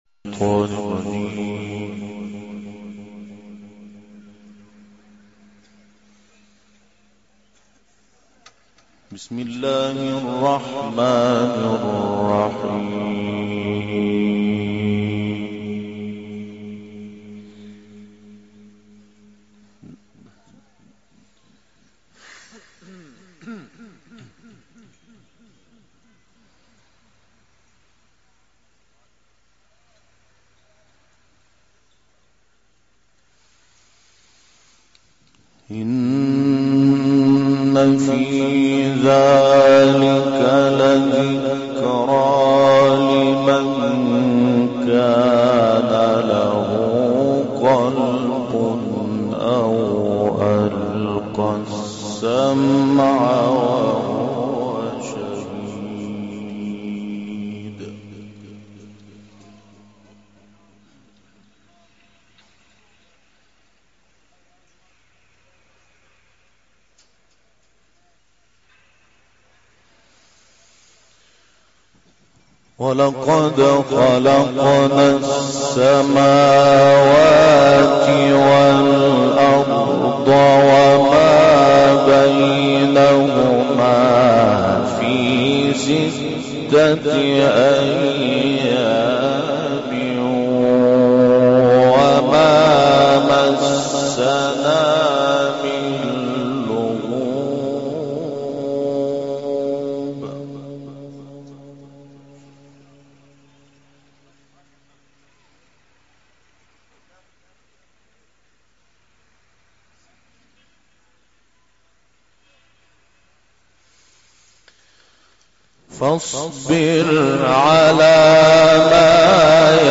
گروه شبکه اجتماعی: تلاوت آیاتی از کلام الله مجید که به تازگی توسط محمود شحات انور اجرا شده است، ارائه می‌شود.